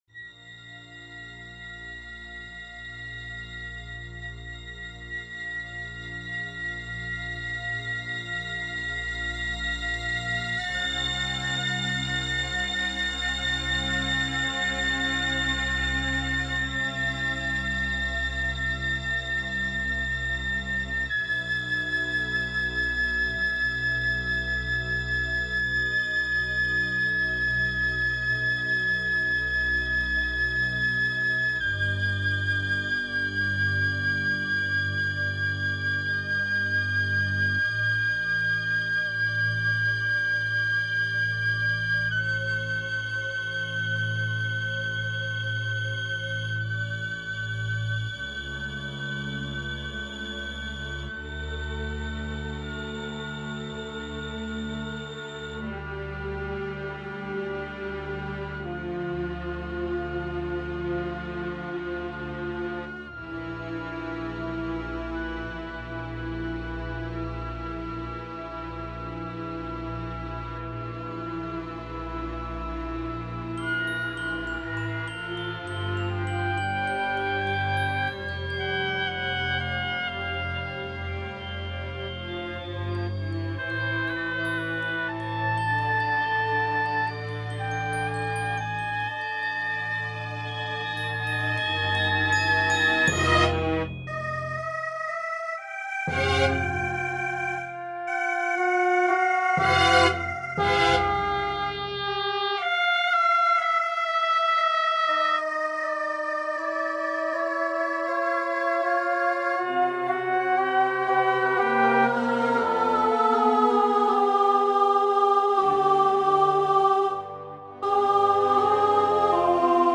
en cuartos de tono
Soprano Orquesta.